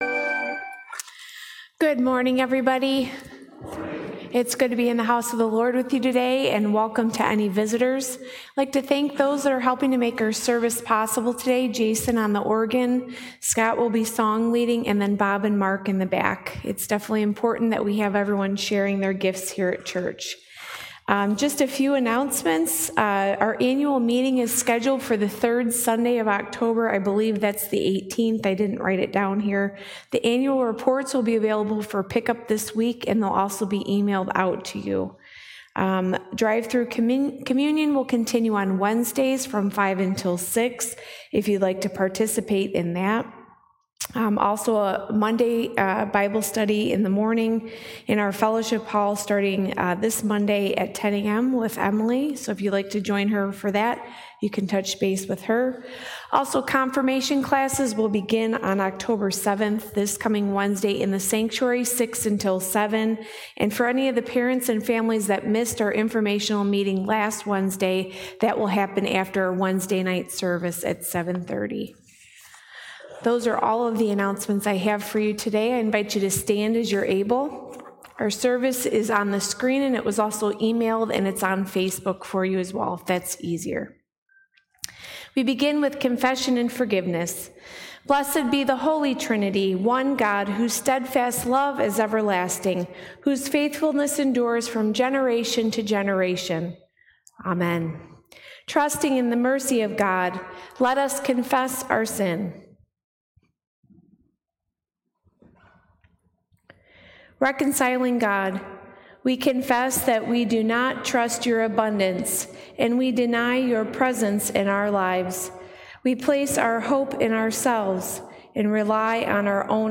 Passage: Isaiah 5:1-7, Luke 10 Service Type: Sunday Worship Service
SundayServiceOct4_2020.mp3